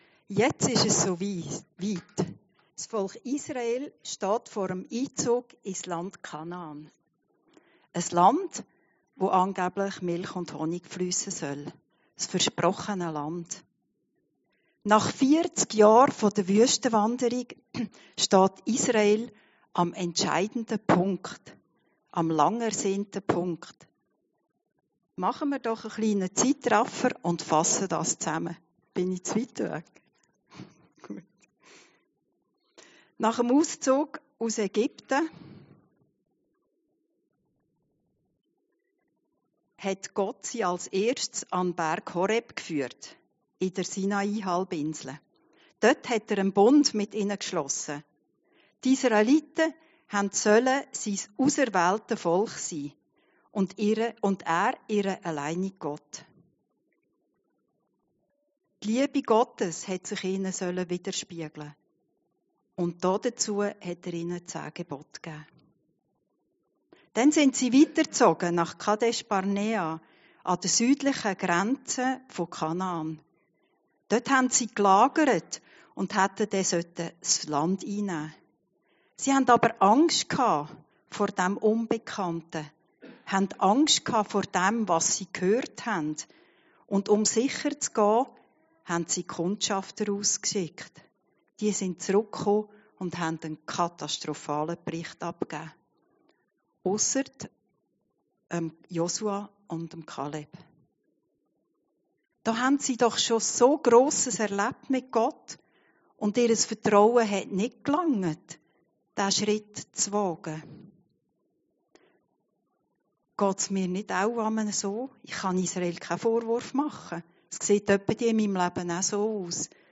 Predigten Heilsarmee Aargau Süd – Wüstenzeiten